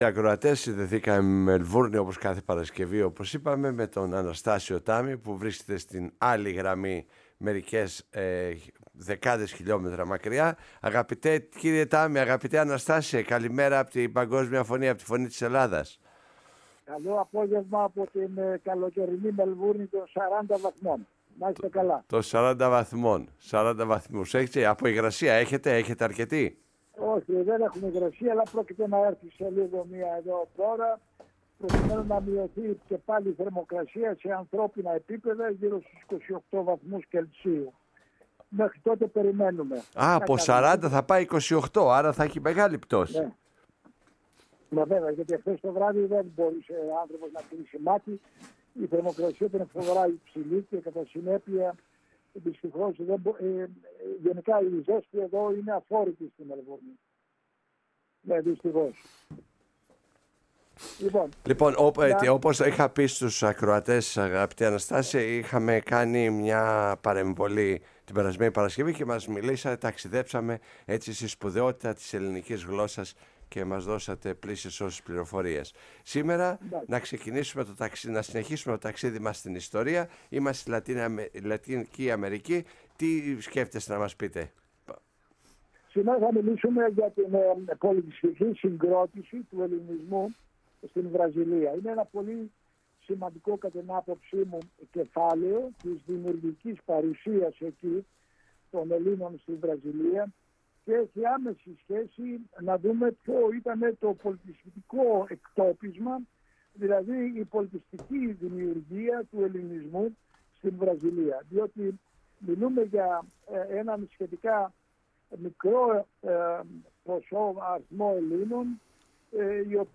στην εκπομπή «Η Παγκόσμια Φωνή μας» στο ραδιόφωνο της Φωνής της Ελλάδας